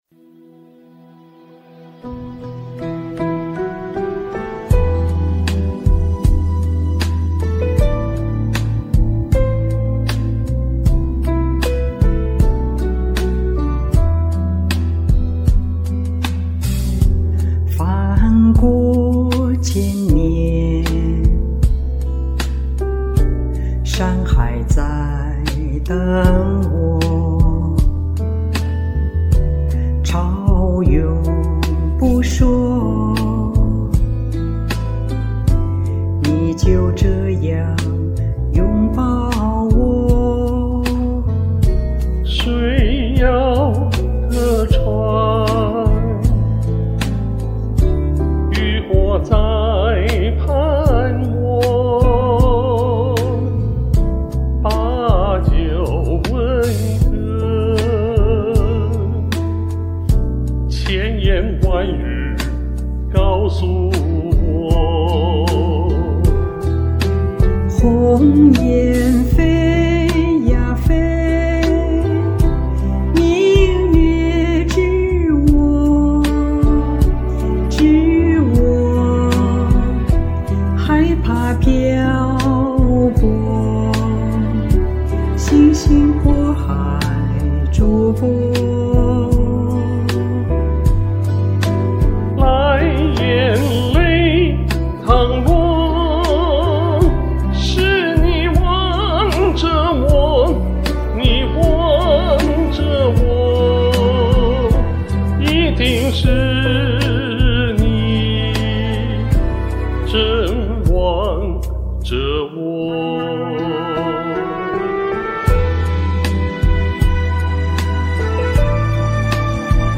非常和谐好听，有些感伤凄凉，非常感人！
精彩的二重唱，大赞！
这歌有一种内敛含蓄的美。